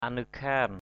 /a-nɯ-kʰa:n/ (d.) tác phẩm = œuvre écrite. a literary work.